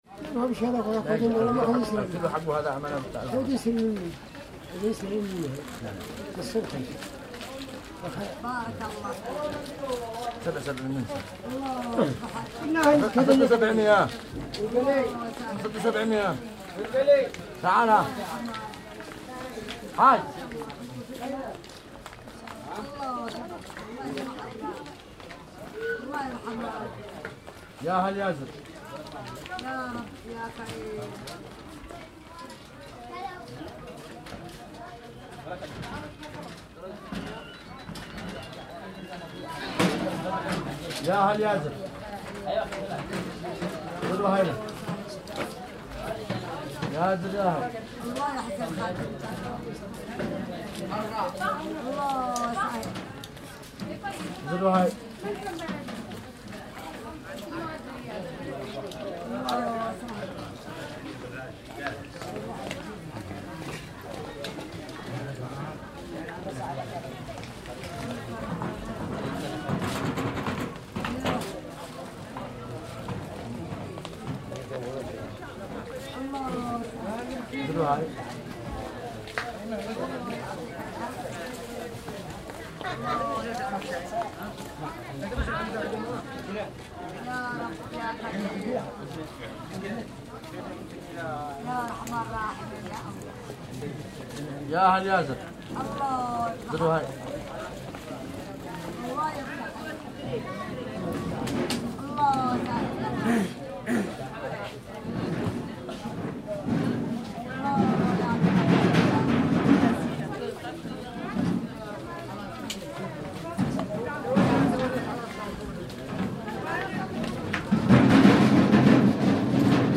14 Field Recordings of Yemen
03 souq – market. Old San’a. Sitting on a side of a narrow market street inbetween traders.